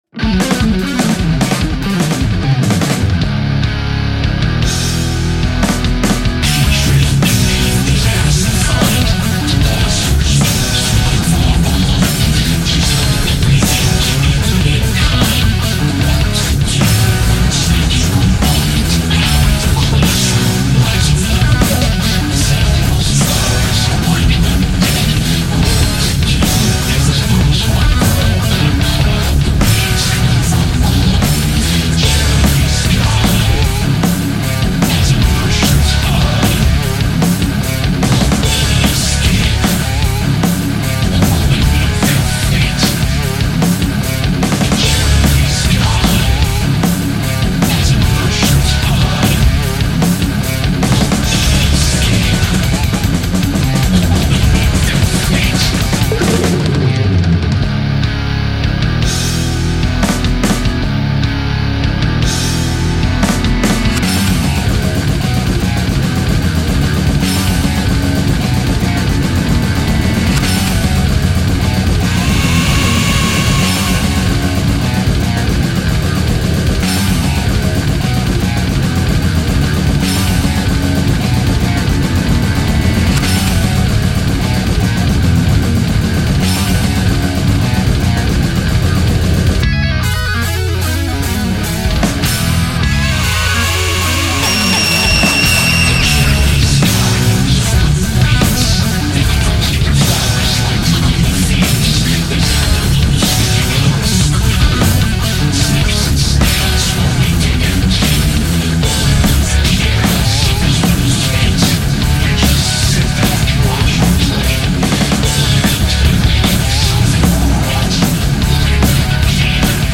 thrash metal